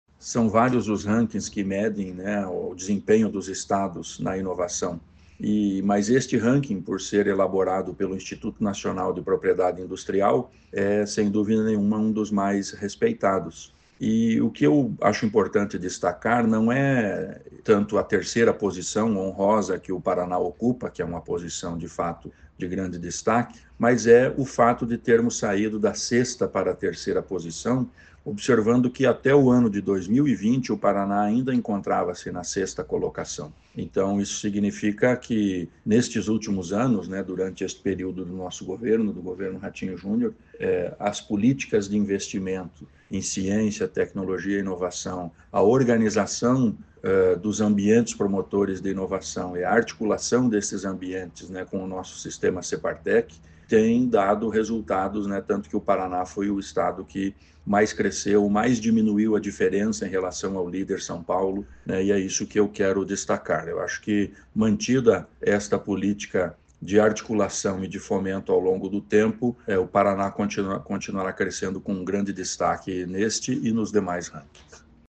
Sonora do secretário da Ciência, Tecnologia e Ensino Superior, Aldo Bona, sobre o salto em inovação de 2020 a 2025